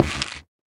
sounds / block / stem / step2.ogg
step2.ogg